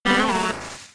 Cri de Mystherbe K.O. dans Pokémon X et Y.